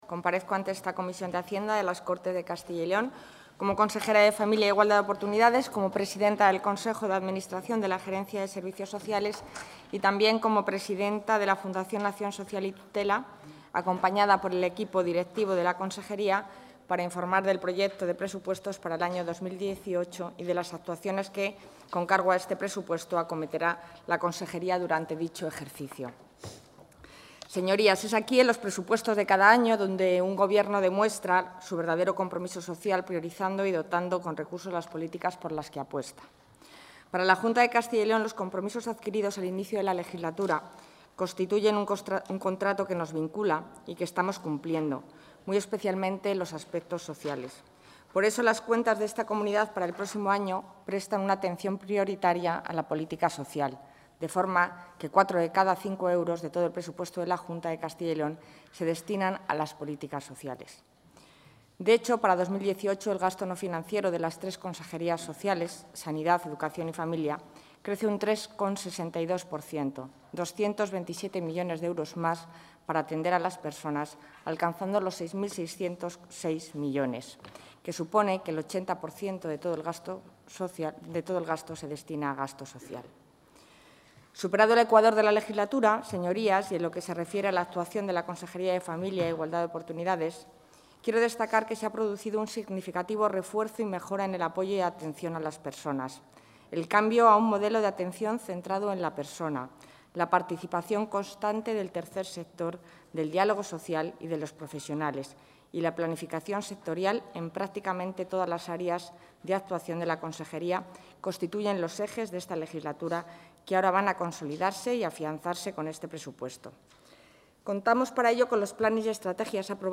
La consejera de Familia e Igualdad de Oportunidades, Alicia García, ha comparecido ante la Comisión de Economía y Hacienda de...
Intervención de la consejera de Familia e Igualdad de Oportunidades.